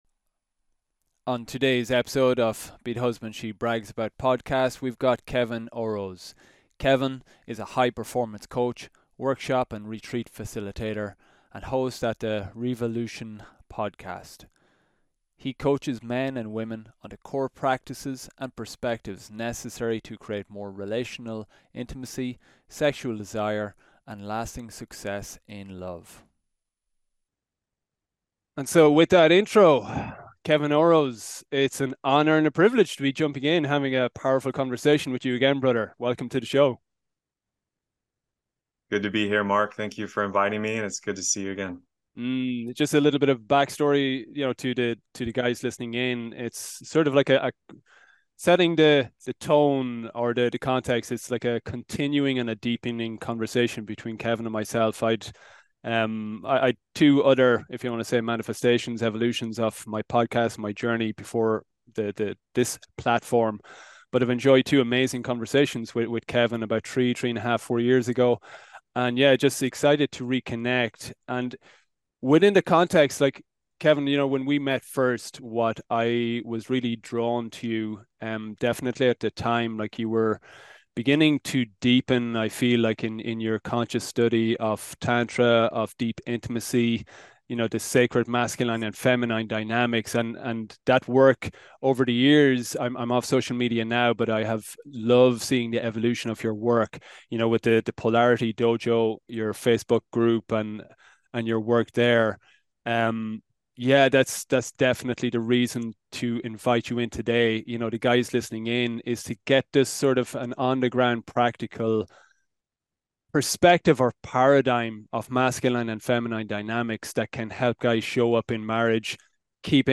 Join a powerful conversation with relationship coach